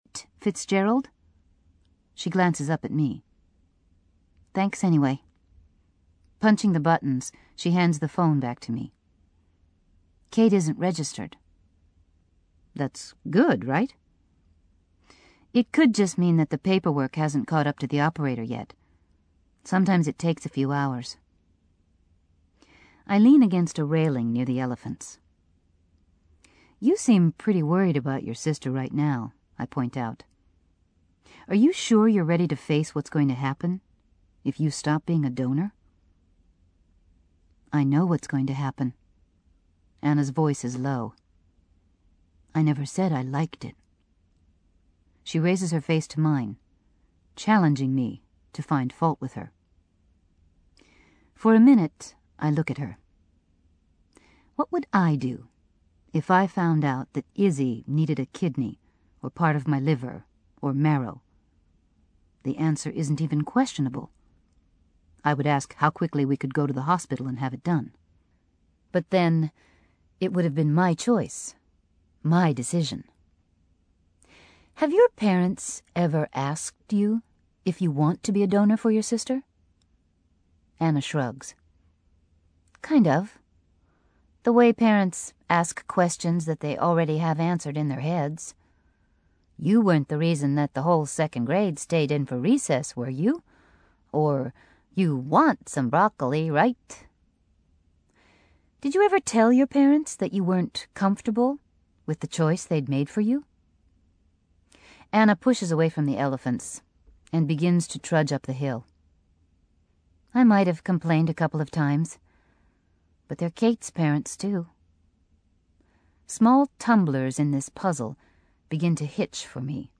英文广播剧在线听 My Sister's Keeper（姐姐的守护者）46 听力文件下载—在线英语听力室